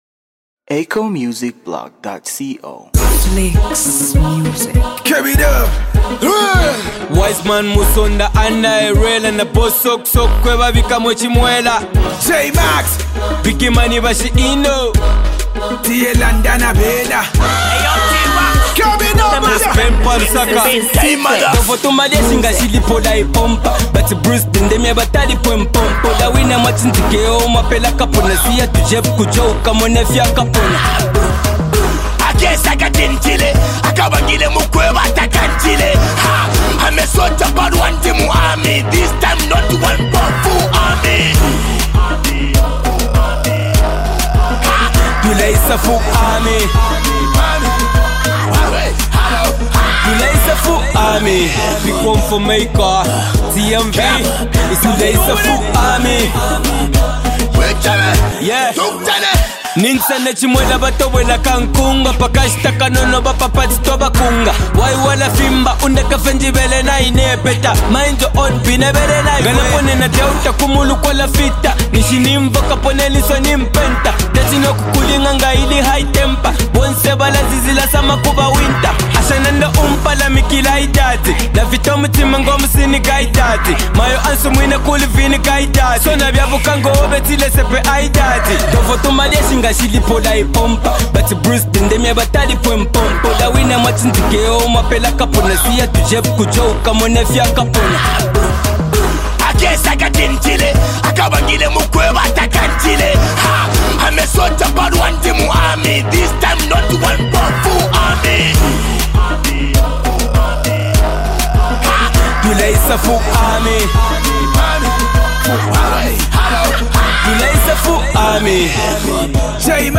remix
Zambian hip-hop